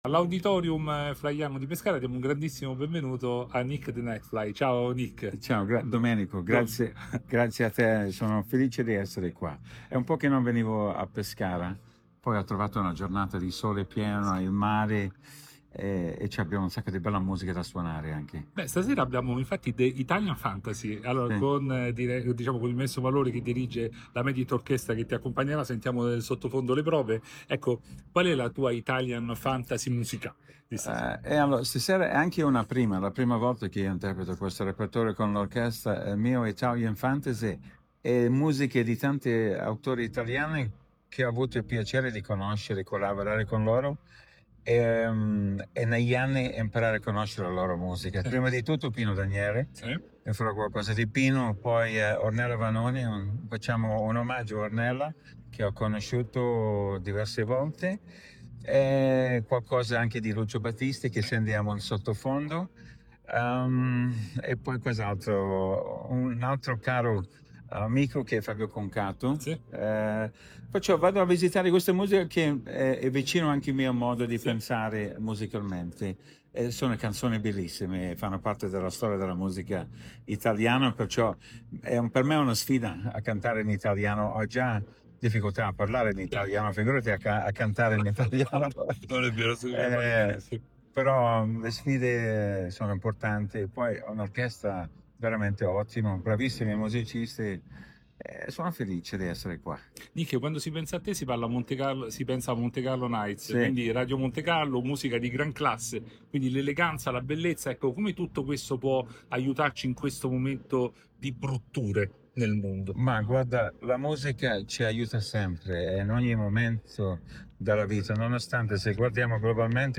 “My Italian Fantasy”: Nick the Nightfly incanta Pescara tra jazz e magia sinfonica. Intervista